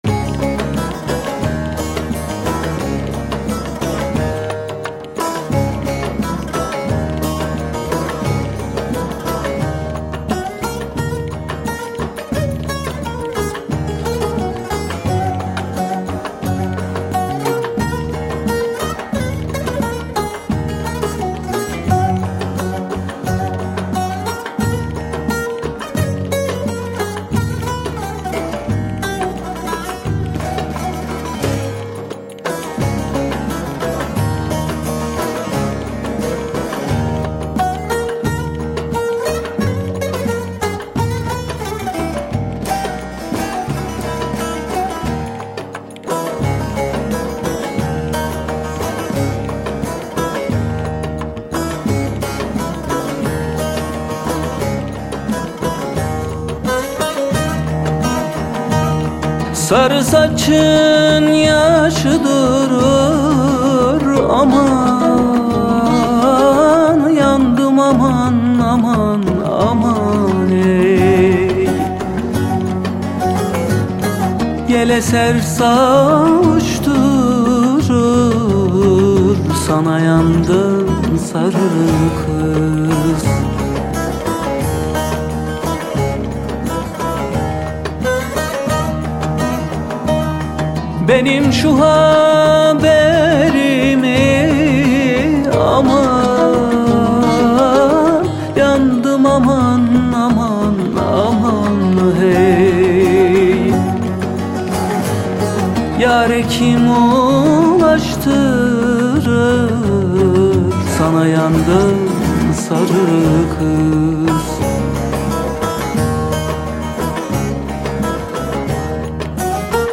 Etiketler: ankara, türkü, müzik, türkiye